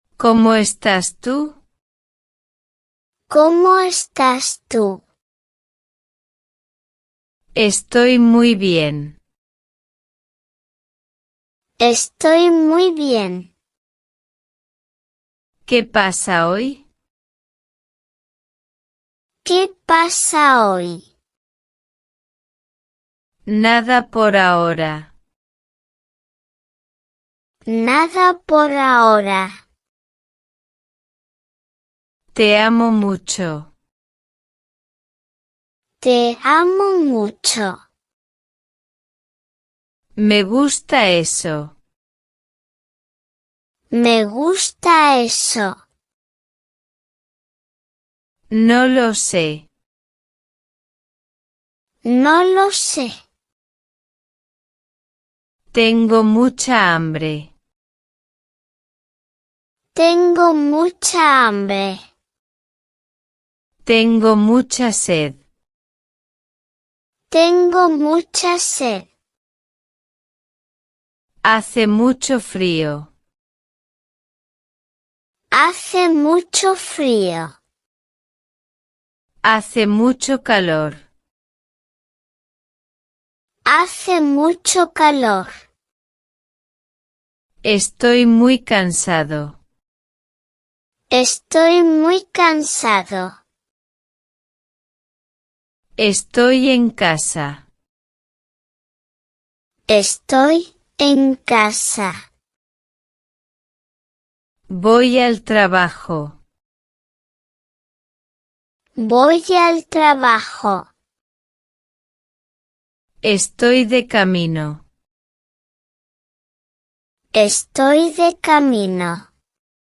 Spanish Dialogues Doctor & Patient | Real Conversations Practice